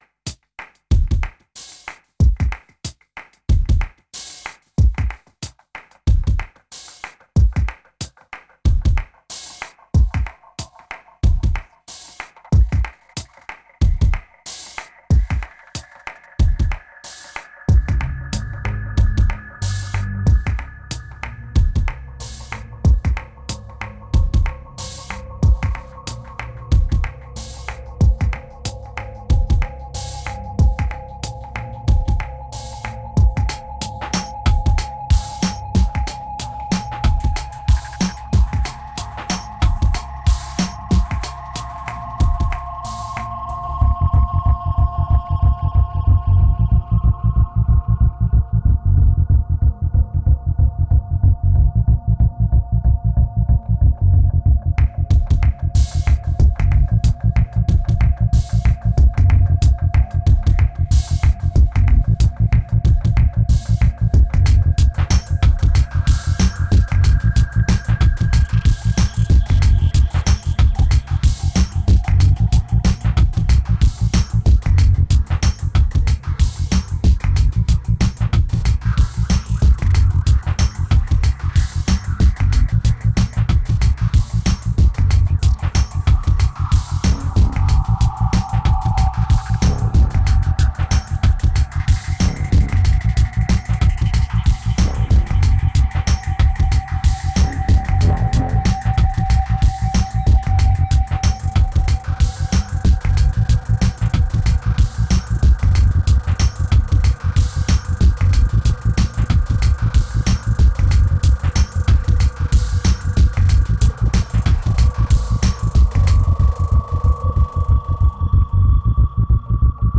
2184📈 - -40%🤔 - 93BPM🔊 - 2010-10-16📅 - -277🌟